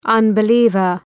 Προφορά
{,ʌnbı’lıvər}